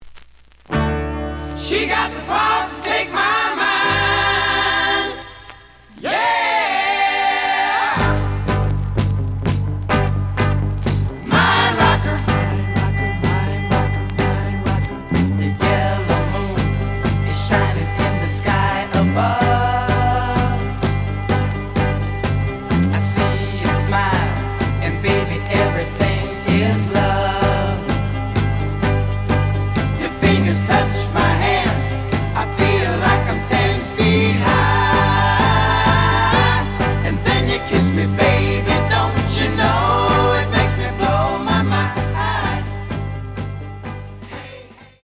The audio sample is recorded from the actual item.
●Genre: Rock / Pop
Slight edge warp.